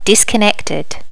disconnected.wav